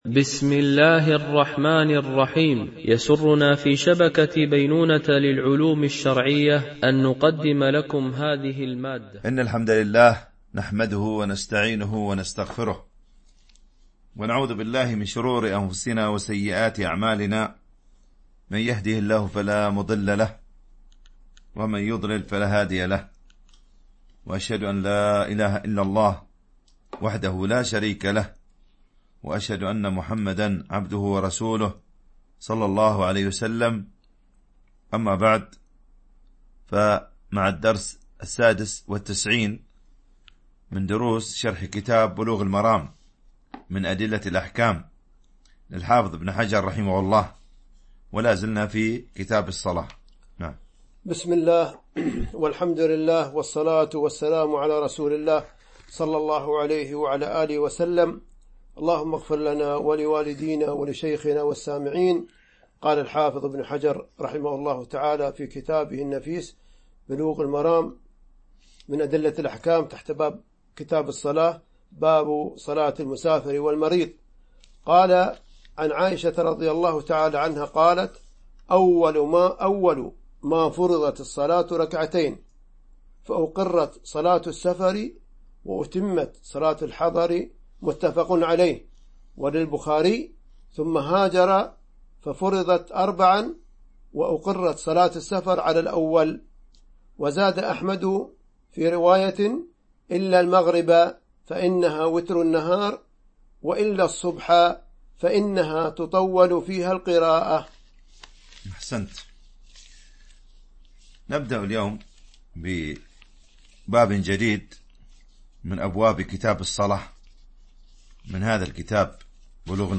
شرح بلوغ المرام من أدلة الأحكام - الدرس 96 ( كتاب الصلاة - باب حكم القصر في السفر - الحديث 429 - 432 )